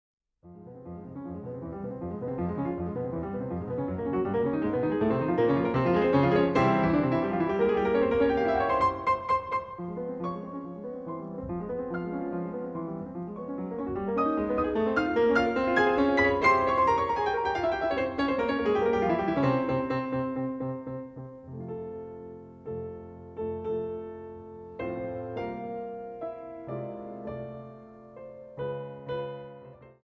Allegro 5:42